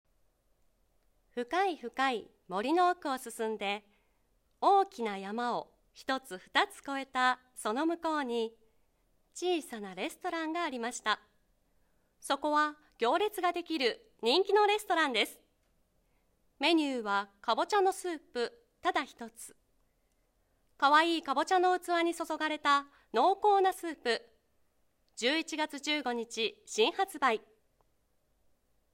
ボイスサンプル
CM